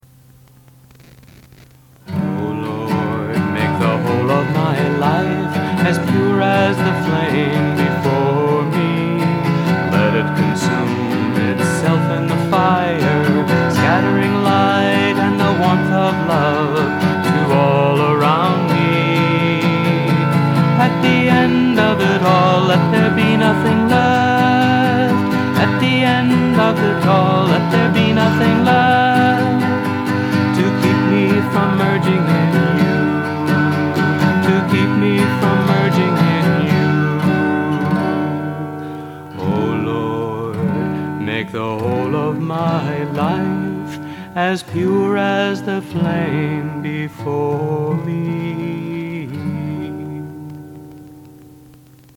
1. Devotional Songs
Major (Shankarabharanam / Bilawal)
8 Beat / Keherwa / Adi
Simple
Medium Fast